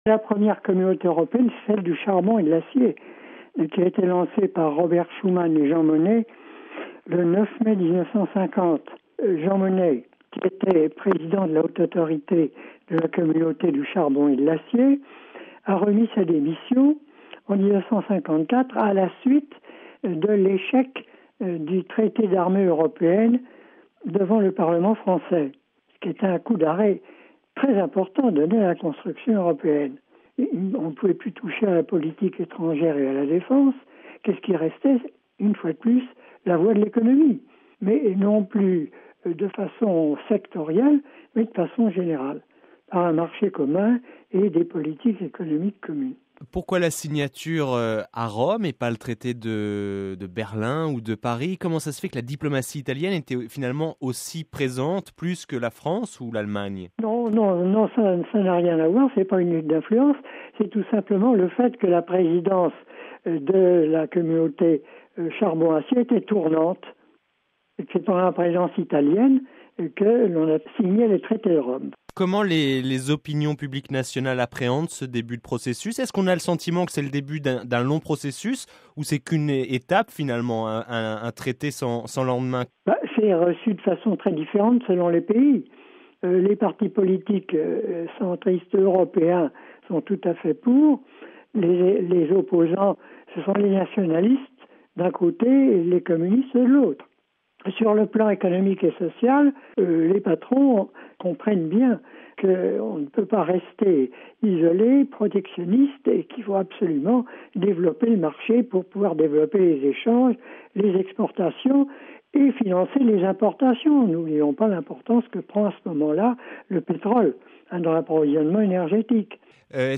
Propos recueillis par